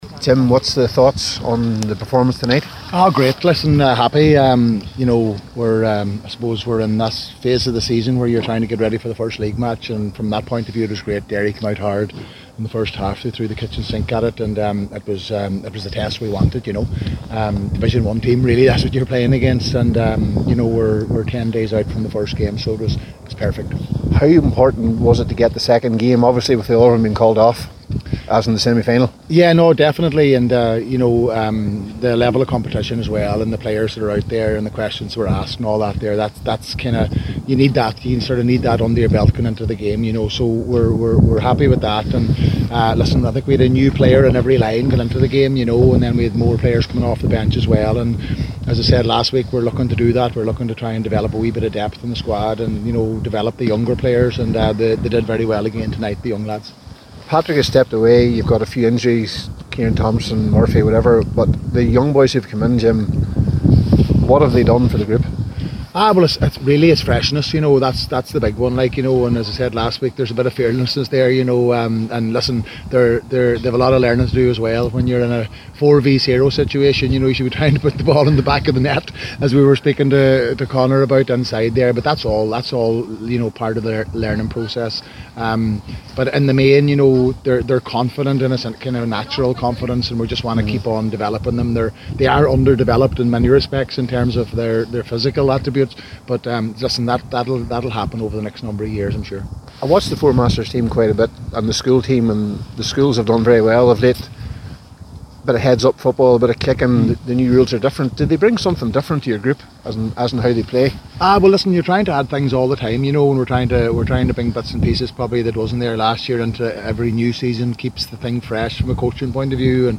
at full time at Celtic Park…